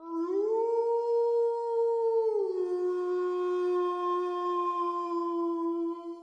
wolf.ogg